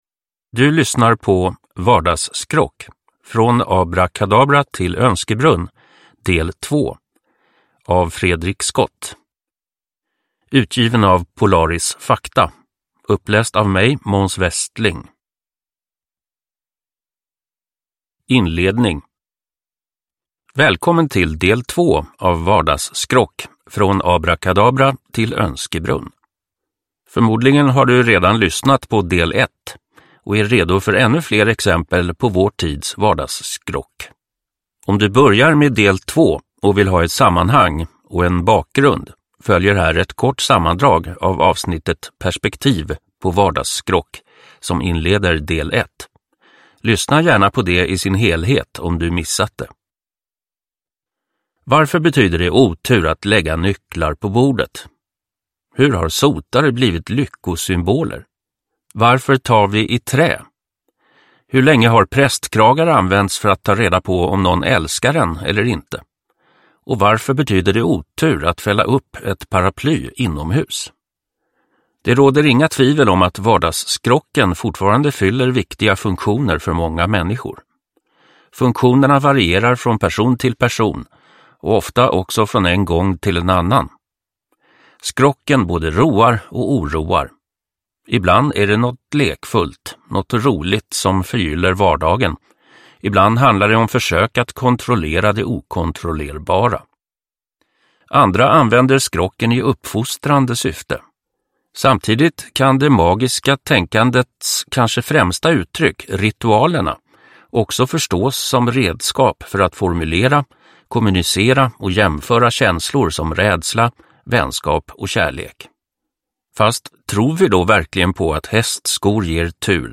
Vardagsskrock: från abrakadabra till önskebrunn, del 2 – Ljudbok – Laddas ner